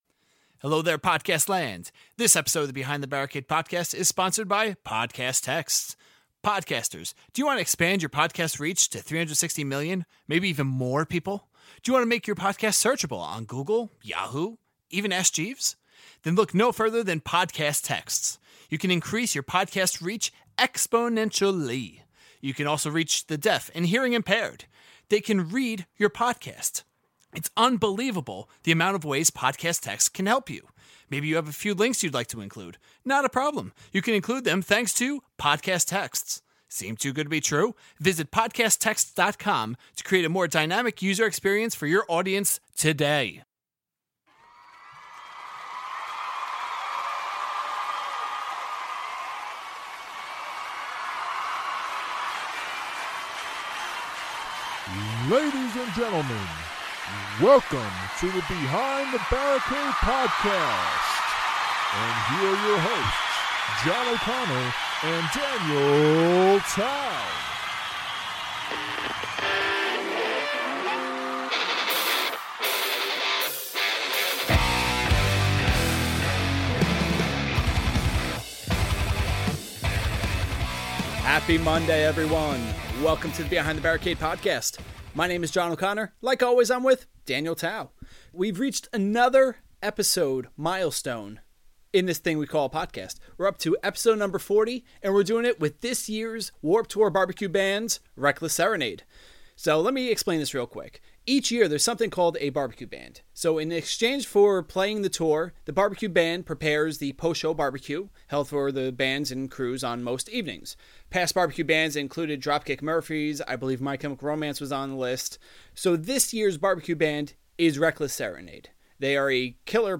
Episode 40 is the third of our Warped Tour 2016 series! This one features Mahopic NY pop punk/indie rock band Reckless Serenade!